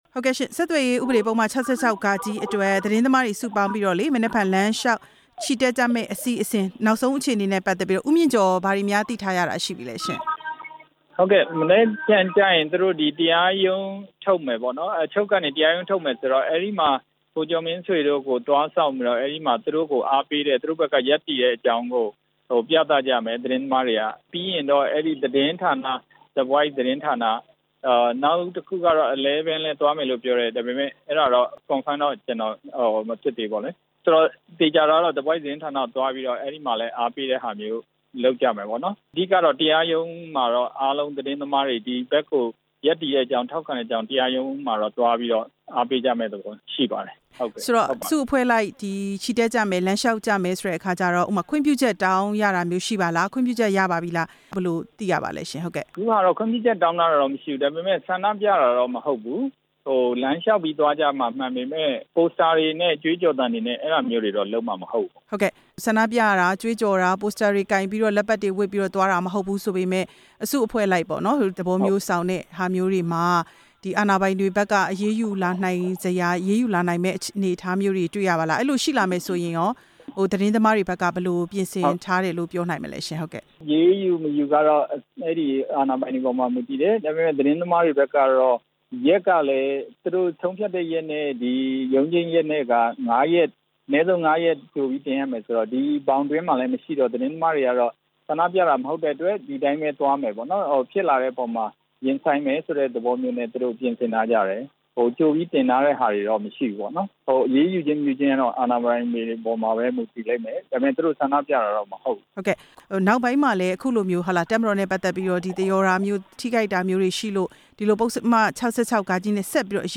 ဆက်သွယ်ရေးဥပဒေပုဒ်မ ၆၆-ဃ နဲ့ သတင်းသမားတွေကို အပြစ်ပေးအရေးယူနေတာတွေ ရပ်တန့်ဖို့အတွက် မနက်ဖြန်မှာ သတင်းသမားတွေ စုပေါင်းလှုပ်ရှားမယ့် အစီအစဉ်နဲ့ ပတ်သက်လို့ ဆက်သွယ်မေးမြန်းစဉ် အခုလို မှတ်ချက်ပေးပြောဆိုခဲ့တာဖြစ်ပါတယ်။